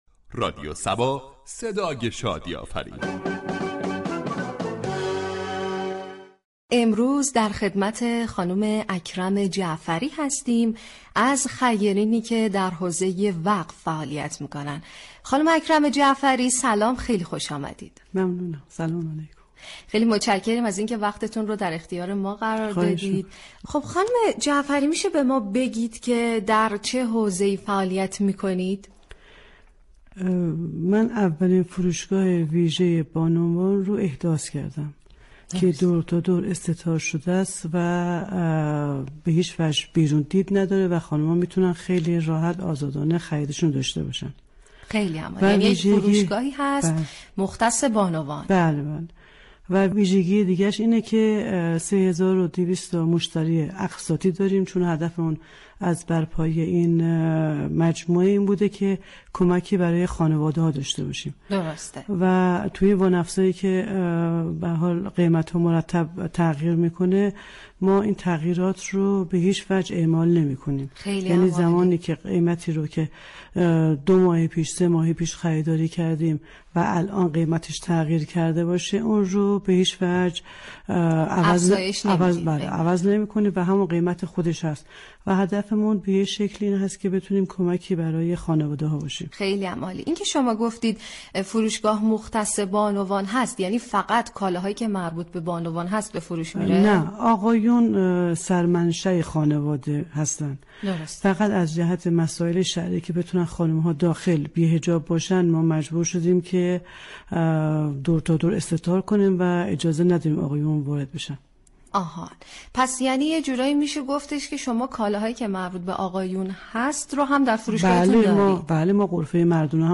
"میزبان" برنامه ای است كه با خیرین در حوزه های مختلف گفتگو مبكند